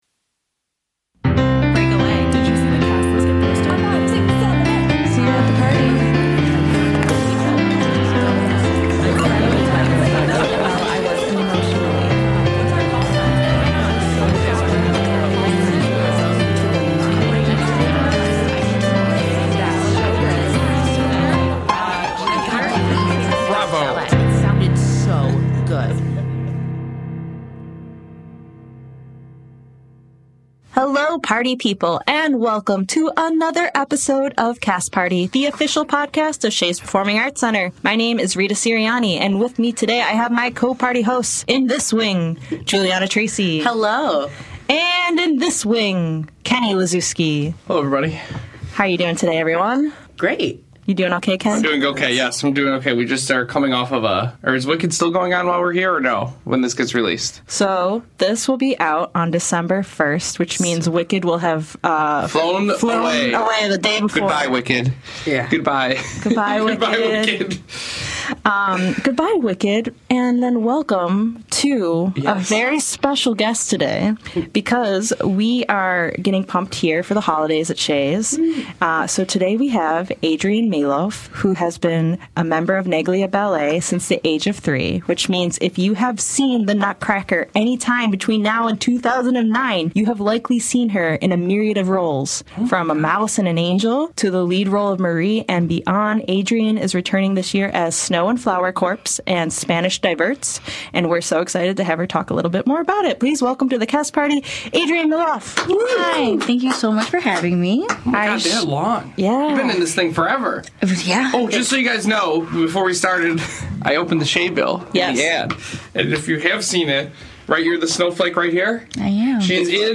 we’re getting into the Holiday Spirit with a magical interview featuring local artist